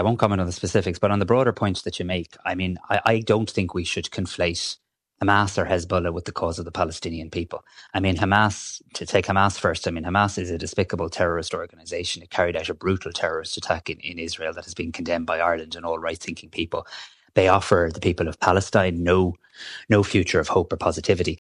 Tanaiste Simon Harris says there’s a clear difference between supporting Palestine and supporting Hezbollah or Hamas: